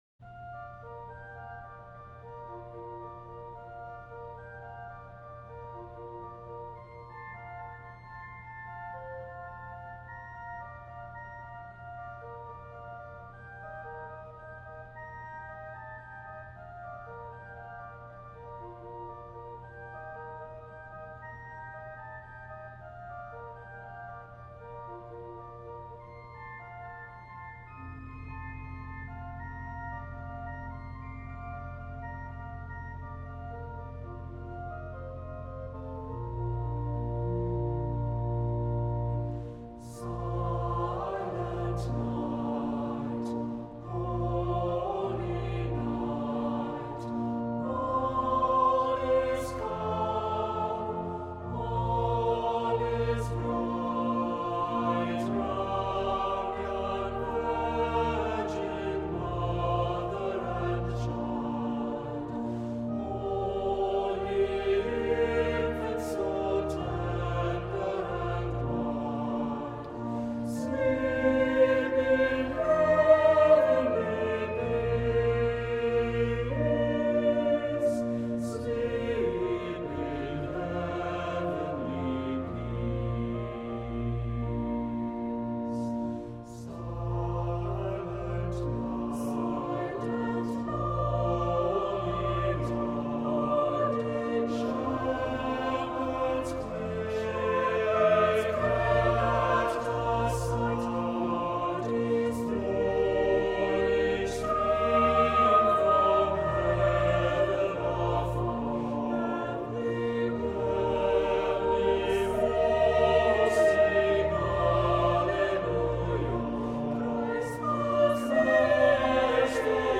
Accompaniment:      Organ
Music Category:      Choral